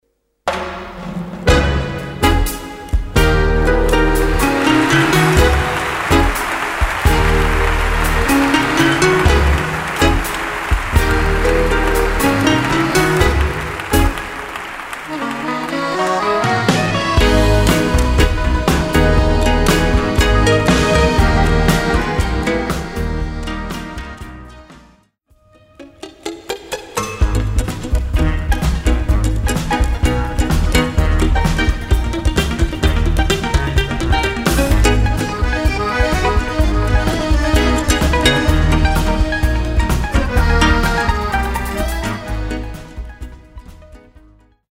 Live recordings from: